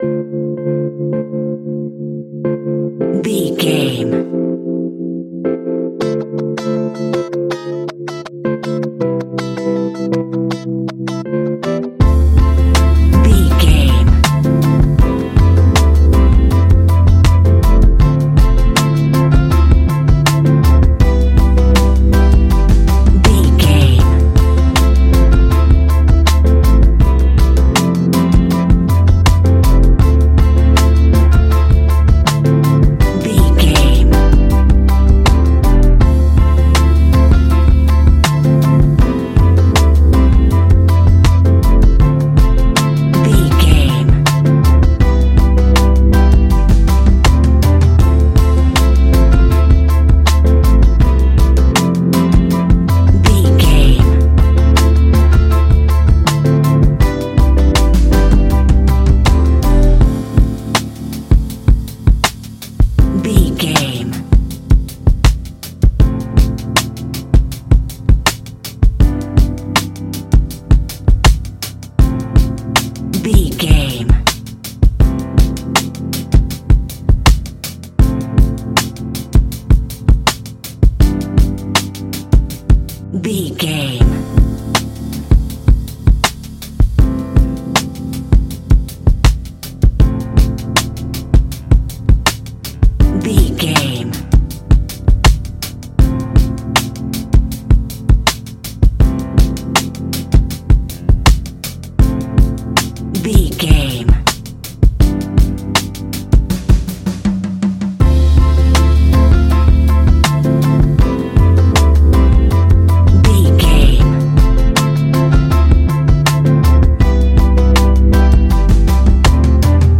Ionian/Major
D
chilled
laid back
Lounge
sparse
new age
chilled electronica
ambient
atmospheric
morphing